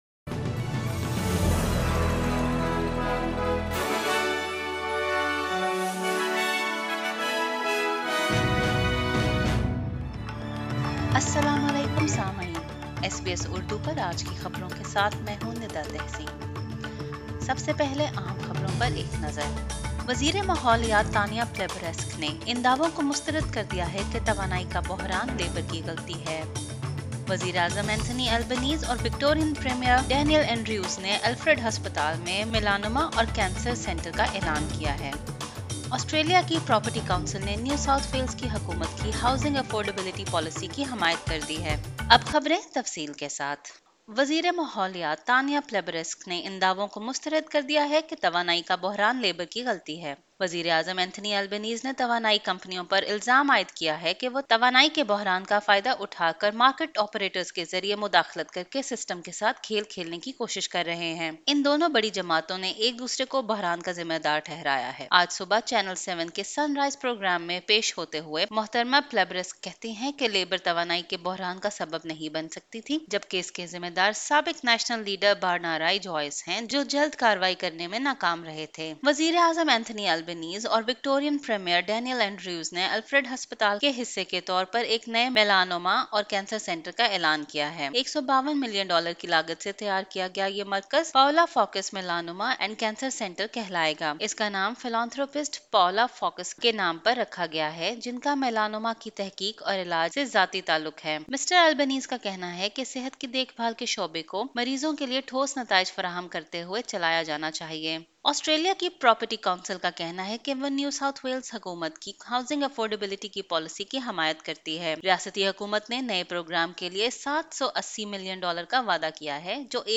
Urdu News 20 June 2022